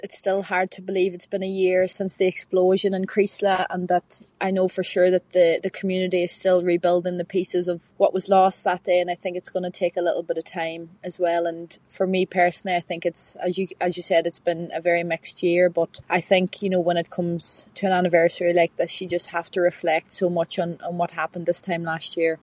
Ireland international footballer Amber Barrett says the village is still reeling from the disaster: